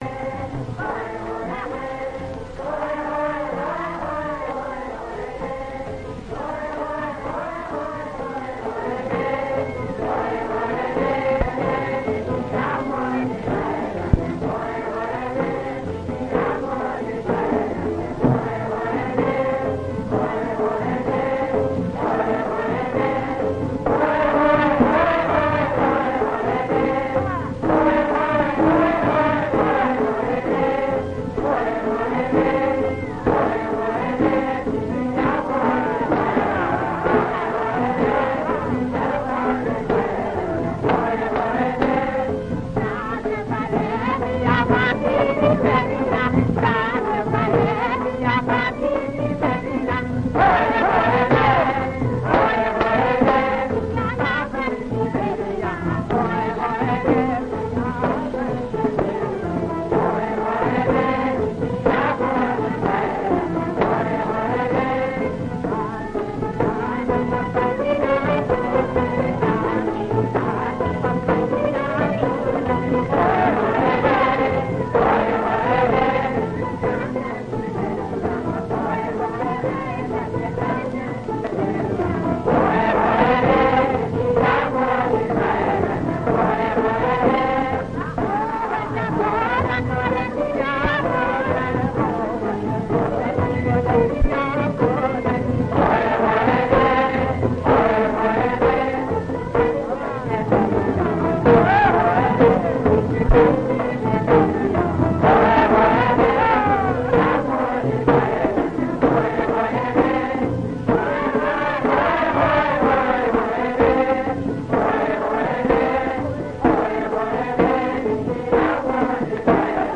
The quality of the original is not that good.